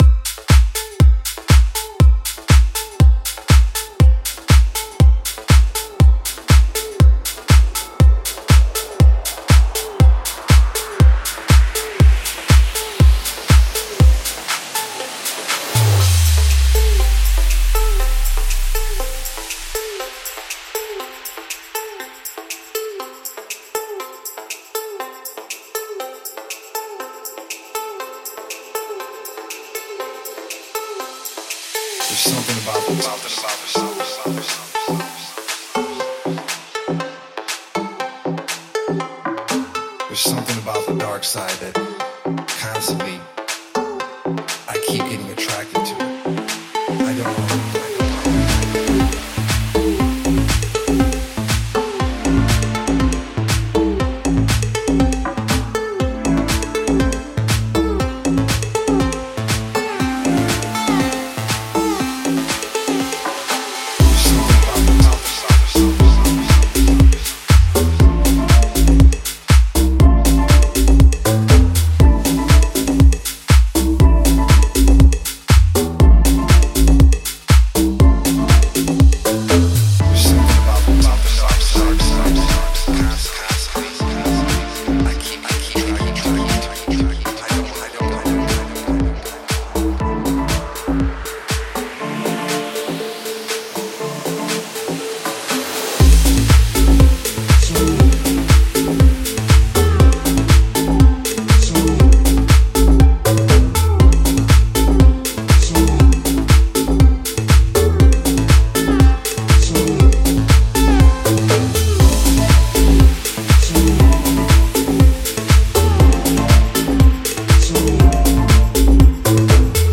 another take of the modern house sound
House